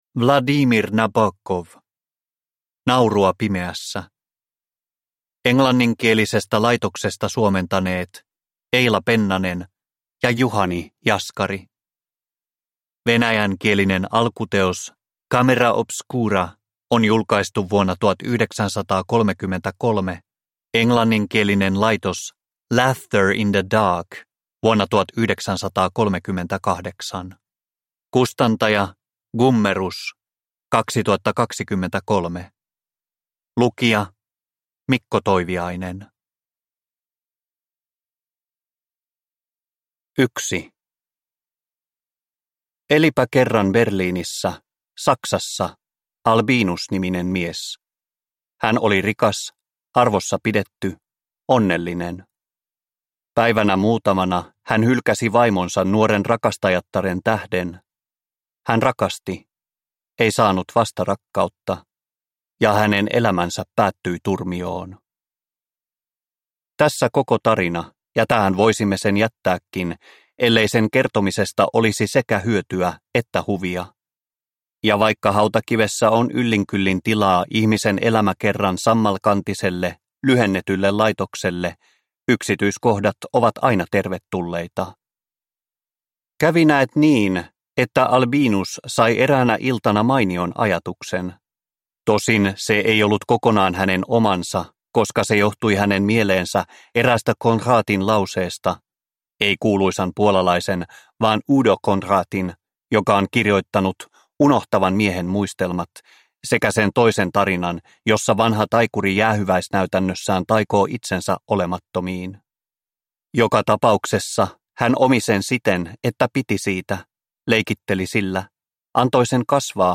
Naurua pimeässä – Ljudbok – Laddas ner